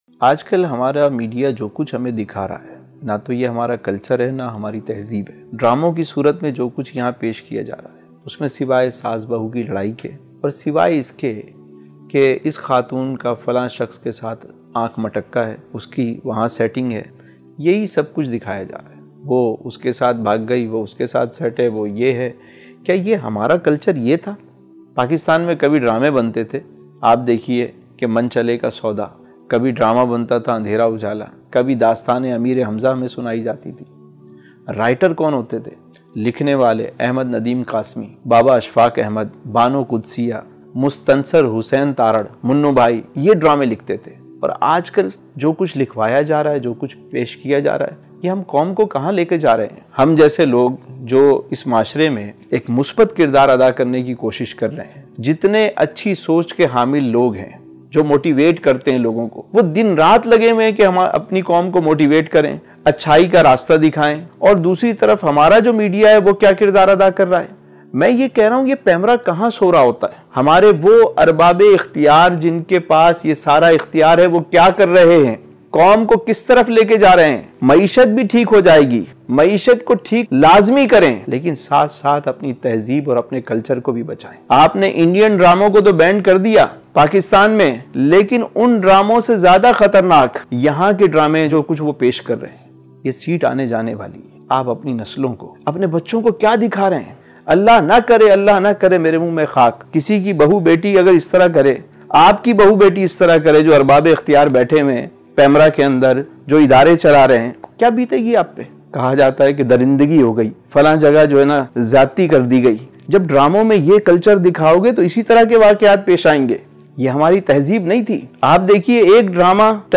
Emotional